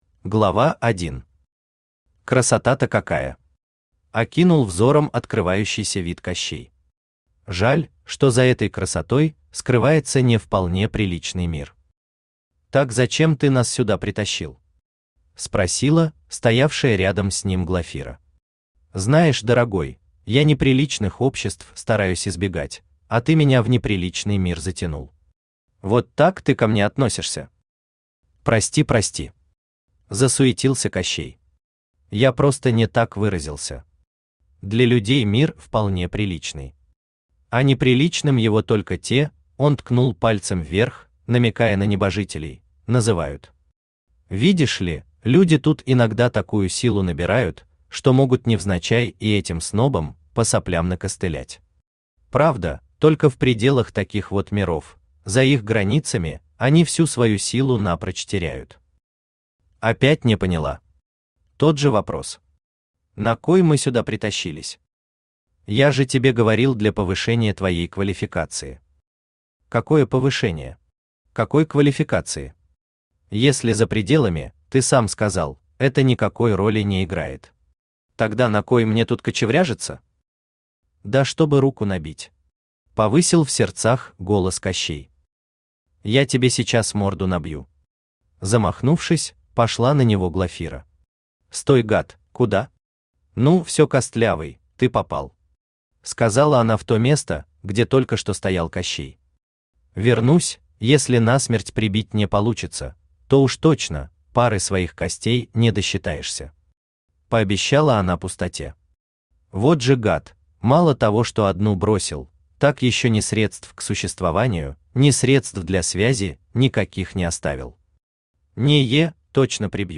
Аудиокнига Глафириада. Книга 1 | Библиотека аудиокниг
Книга 1 Автор Ричард Евгеньевич Артус Читает аудиокнигу Авточтец ЛитРес.